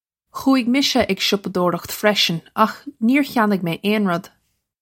Pronunciation for how to say
Khoo-ig misha ig shoppa-dore-ukht freshin akh neer khyan-ig may ayn rud!
This is an approximate phonetic pronunciation of the phrase.